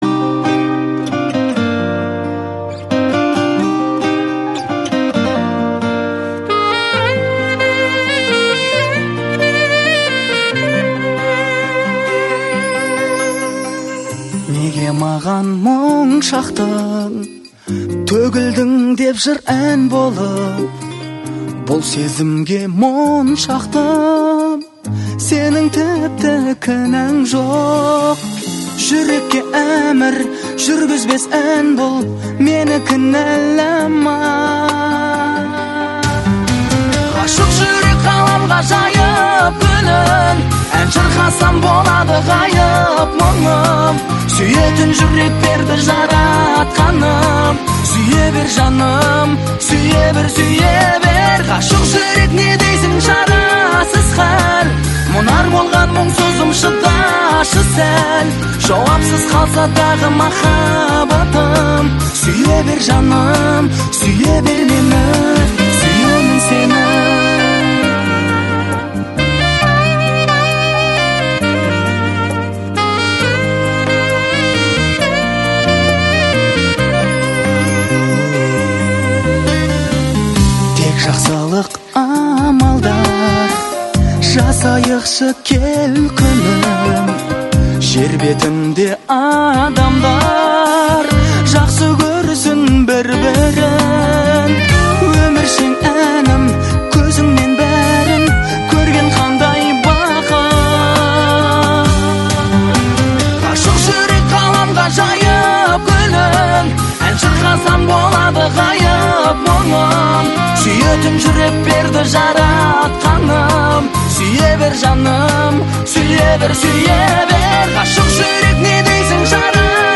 • Качество: 96, Stereo
поп
гитара
мужской голос
спокойные
пианино
Саксофон
романтичные